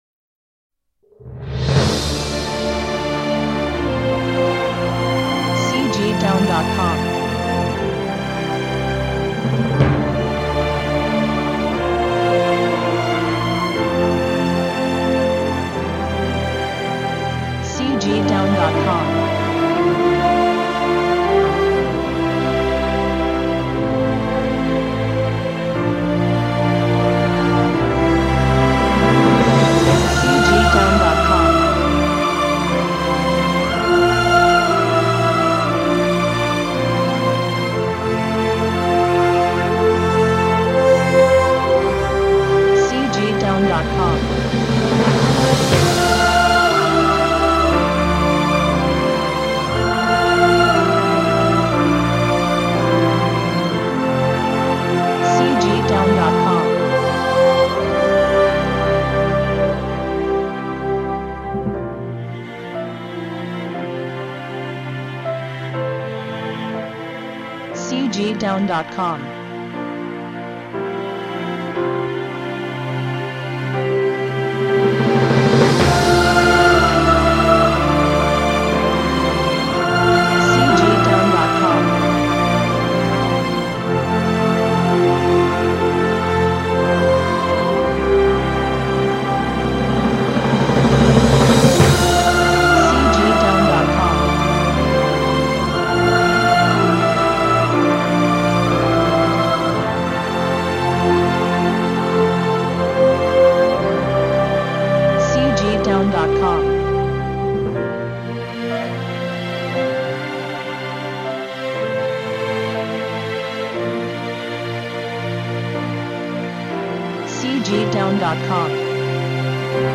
1970-01-01 辉煌感动